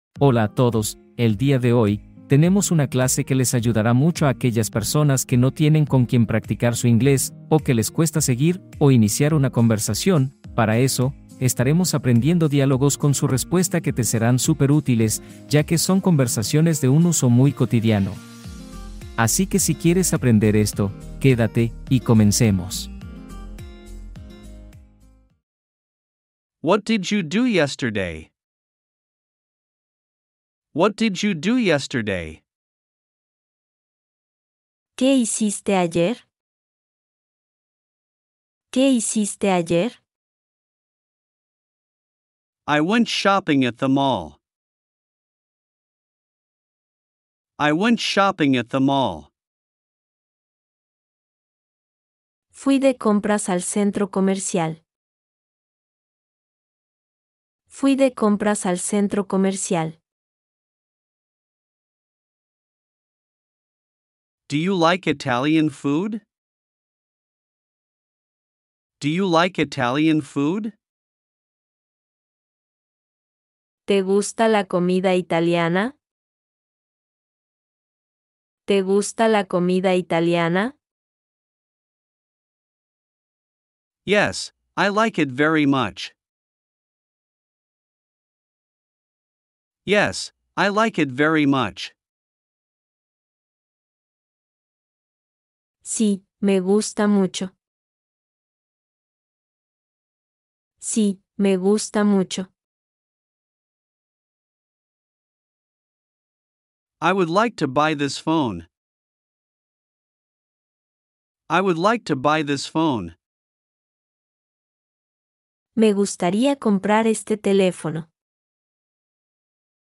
❗ Escucha esta conversación en inglés y aprende rápido